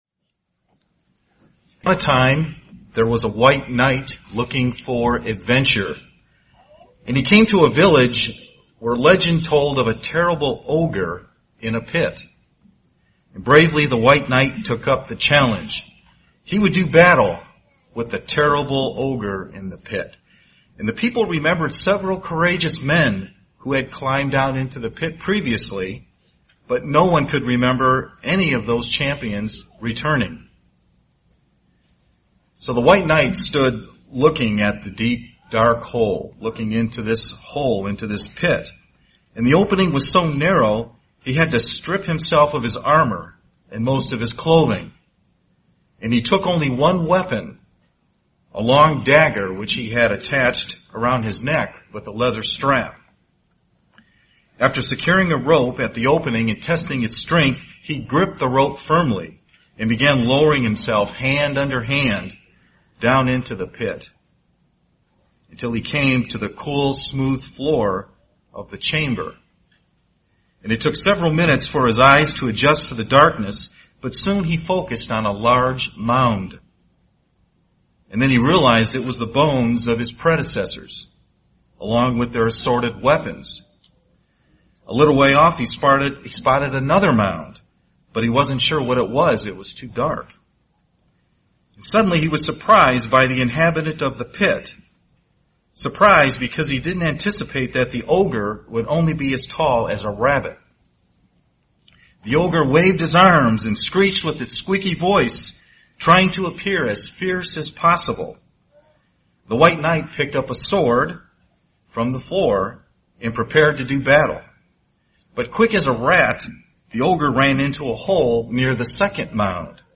UCG Sermon Notes Notes: Luke 12:13 → The Parable of the Rich Fool.